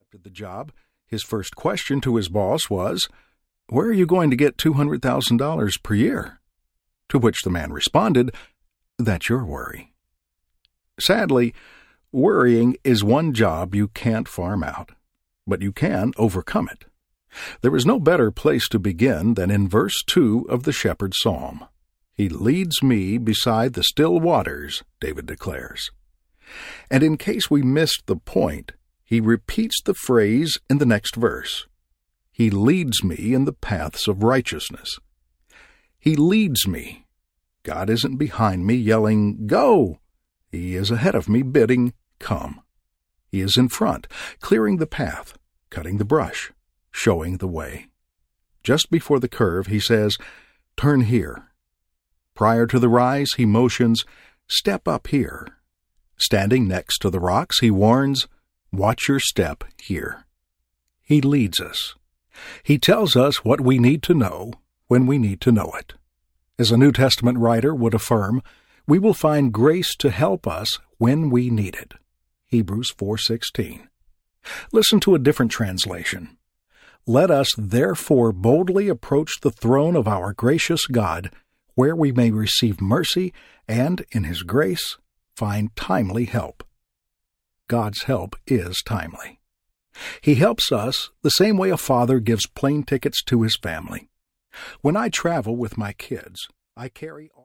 Narrator
4.36 Hrs. – Unabridged